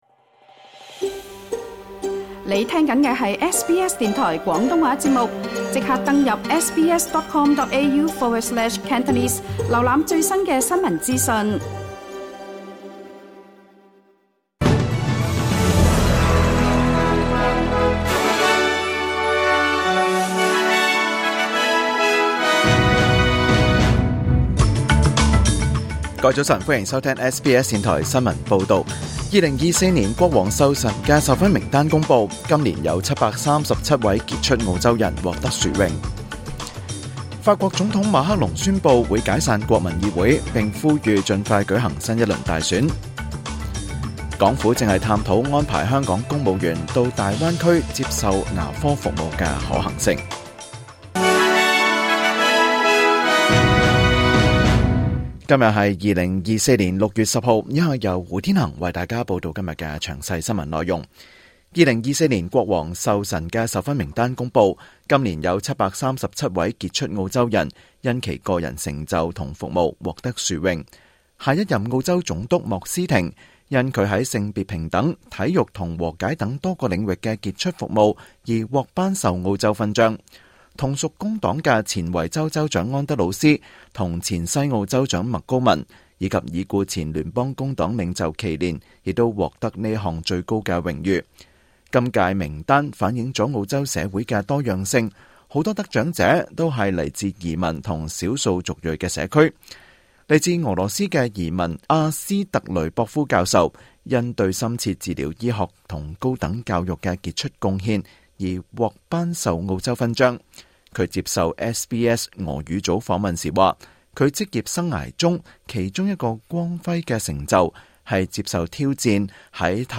SBS廣東話新聞（2024年6月10日）
2024年6月10日SBS廣東話節目詳盡早晨新聞報道。